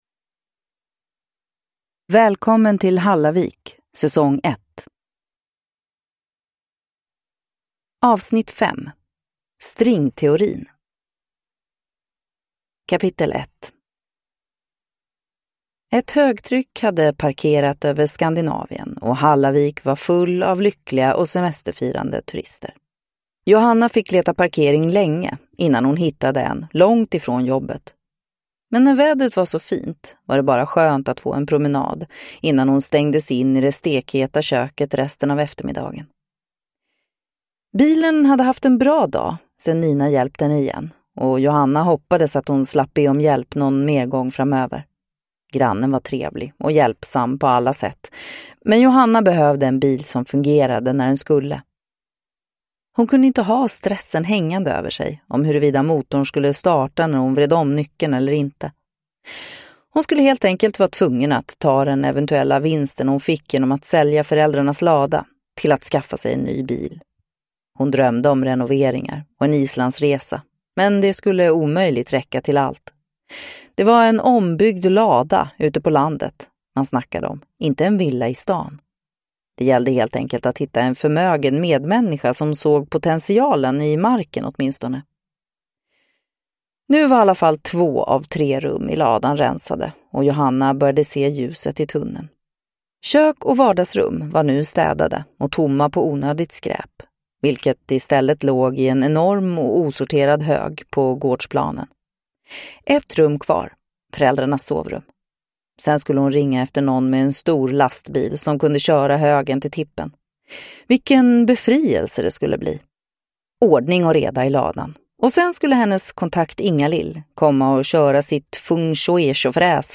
Stringteorin (S1E5 Välkommen till Hallavik) – Ljudbok